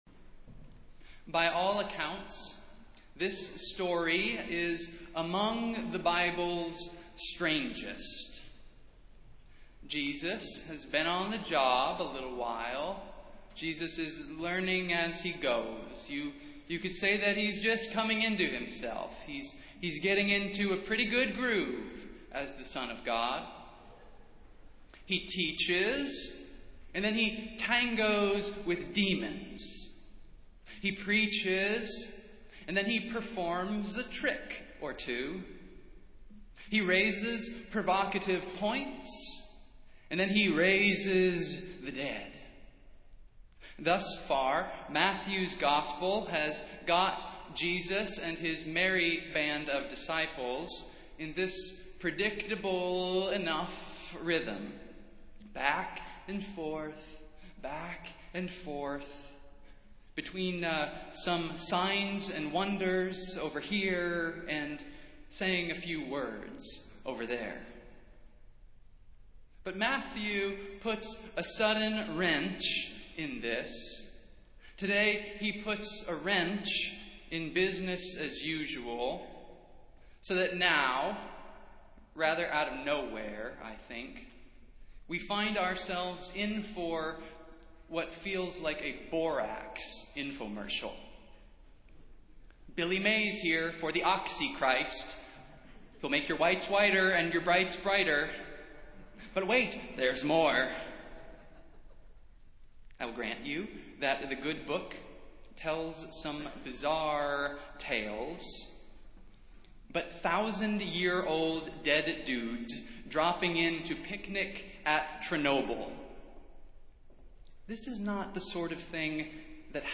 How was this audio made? Festival Worship - Transfiguration Sunday